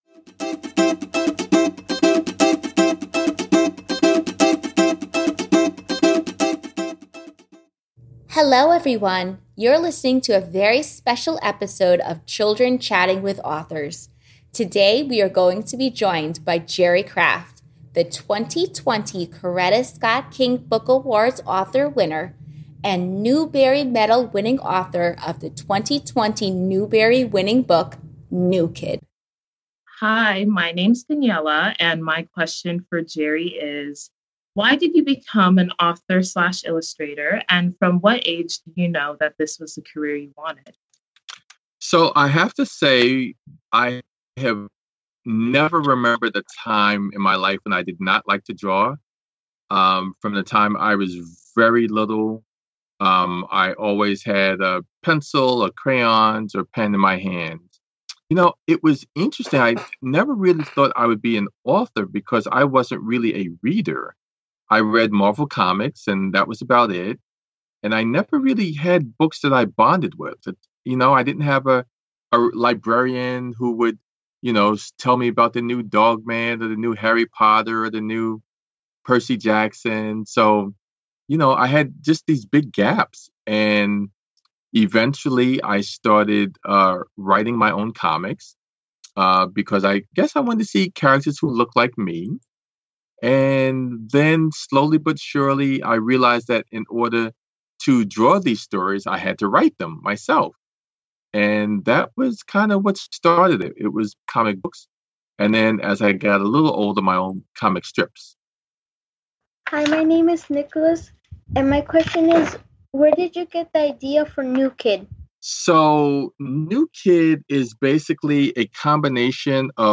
Children Chatting With Jerry Craft
Join the Children Chatting with Authors Podcast as they interview 2020 Newbery winning author Jerry Craft about his award-winning and best-selling book, New Kid and about being an author and illustrator.